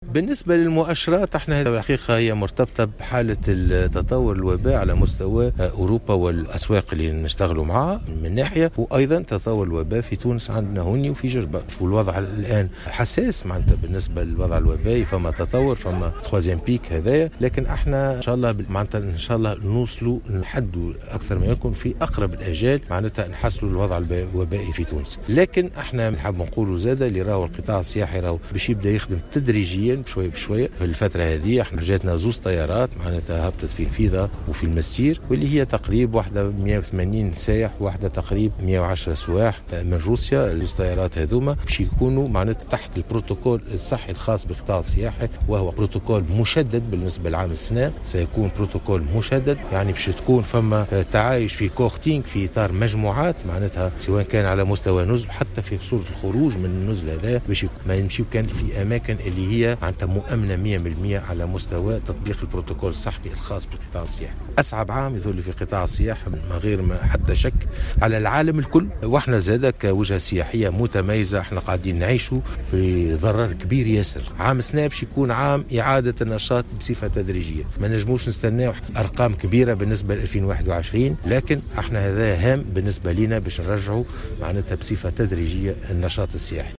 قال وزير السياحة والصناعات التقليدية حبيب عمار، في تصريح لمراسلة الجوهرة اف أم، اليوم الخميس، إن نجاح الموسم السياحي في تونس من عدمه، مرتبط بتطور الوضع الوبائي في الدول الأوروبية والاسواق التقليدية، وكذلك في تونس، ما يستدعي السيطرة على انتشار فيروس كورونا للانطلاق في هذا الموسم في أحسن الظروف.